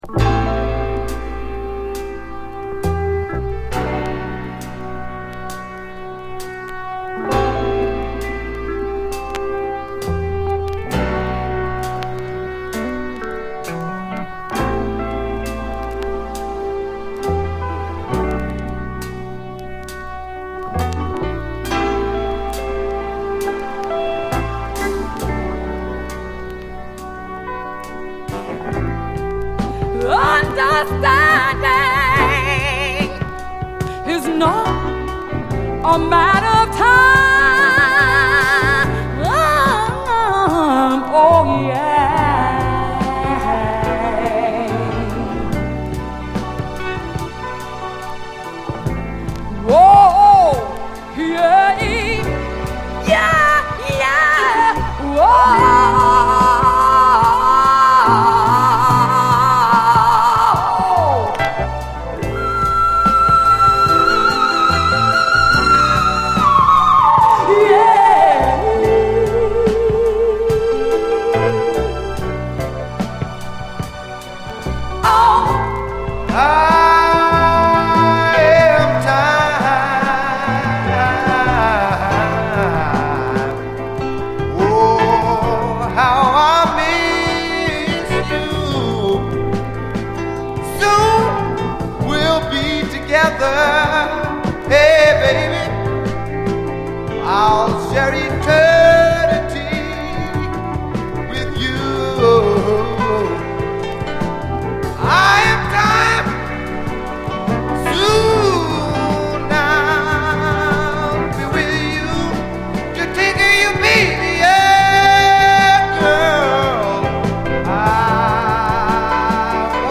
SOUL, 70's～ SOUL, 7INCH
スピリチュアルなムードのメロウ・ジャジー・ソウル
謎の男女デュオが残した、ニューソウル７インチ！
ピアノ＆シンセ、デュエット・ヴォーカルが荘厳に響く、スピリチュアルなムードがカッコいい一曲！